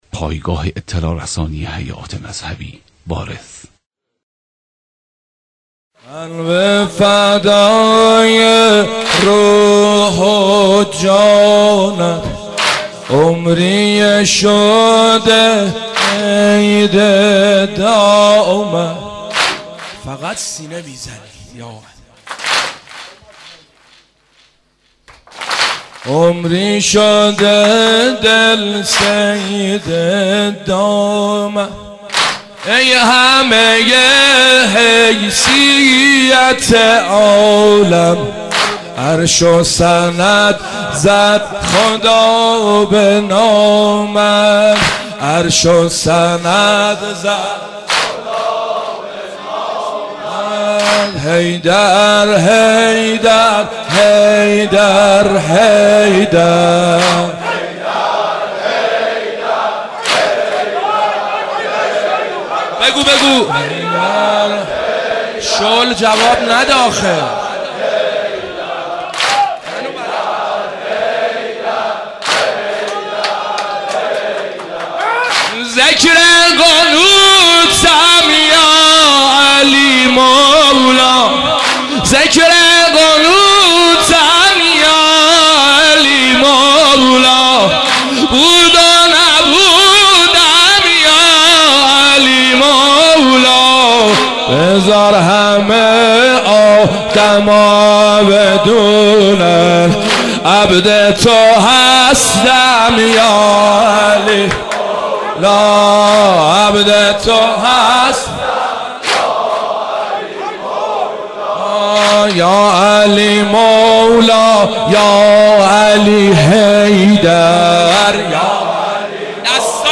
مداحی
به مناسبت شهادت امیرالمومنین (ع)